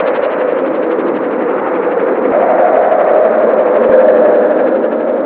blizzard.au